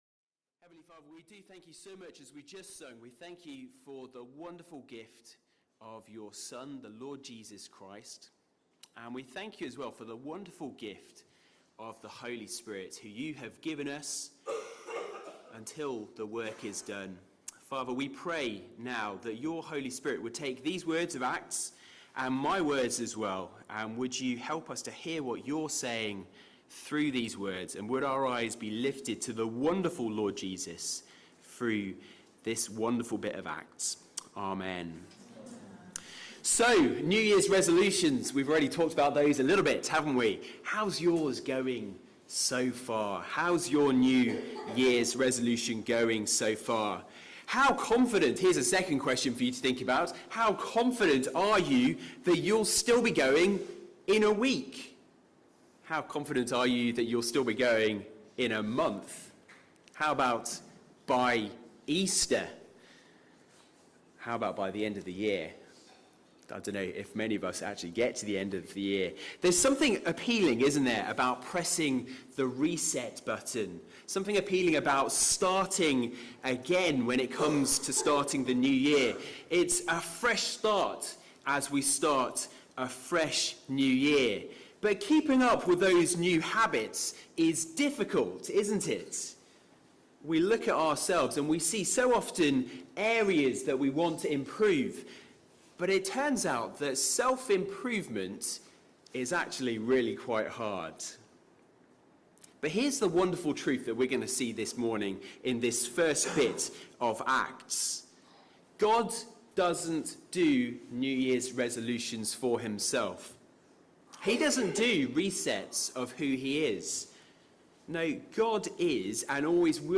Bible Talks | Christ Church Central | Sheffield